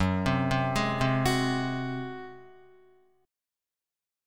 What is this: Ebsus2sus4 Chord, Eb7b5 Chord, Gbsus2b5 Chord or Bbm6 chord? Gbsus2b5 Chord